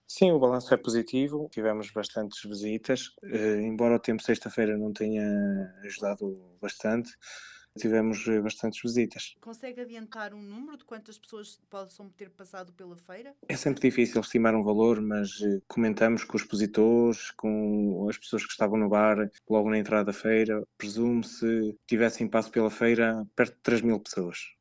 A Junta de Freguesia, entidade organizadora, faz um balanço positivo, com as expectativas superadas quanto ao número de visitantes, como referiu o presidente, Miguel Reis: